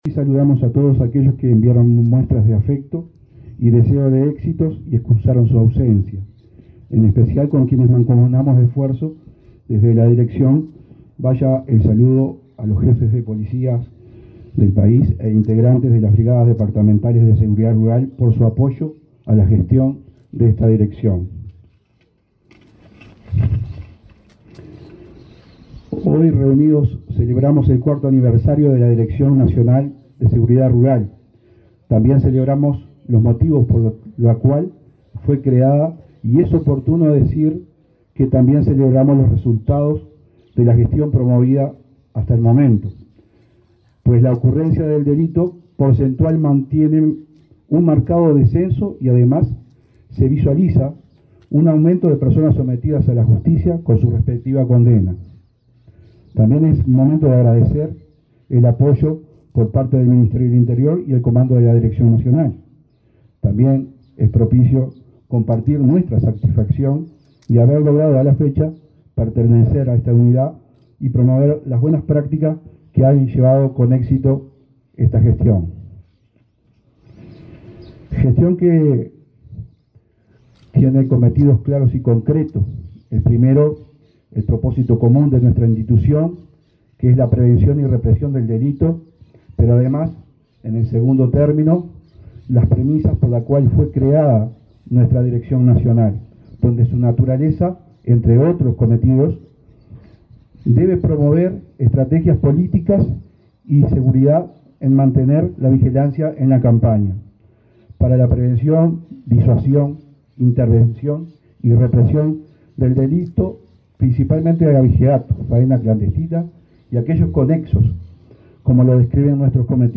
Palabras de autoridades en aniversario de la Dirección Nacional de Seguridad Rural
El director nacional de Seguridad Rural, Ernesto Cossio, y el subsecretario del Ministerio del Interior, Pablo Abdala, participaron, este jueves 4 en